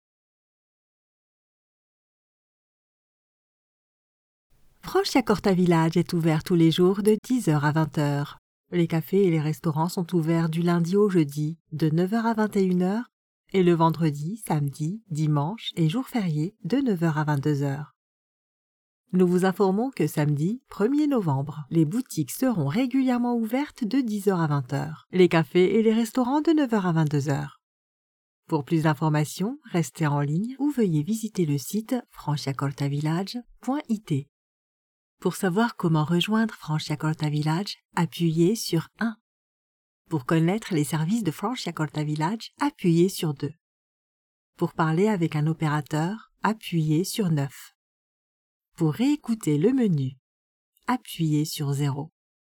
Never any Artificial Voices used, unlike other sites.
On Hold, Professional Voicemail, Phone Greetings & Interactive Voice Overs
Adult (30-50) | Yng Adult (18-29)